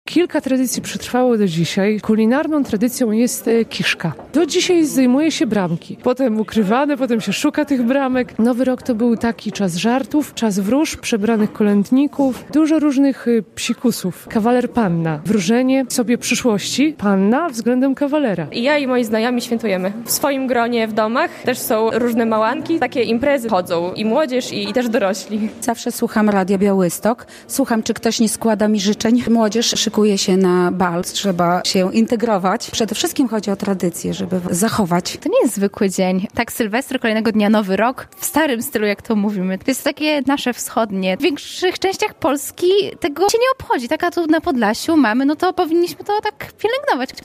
Szczodry wieczar na Podlasiu - relacja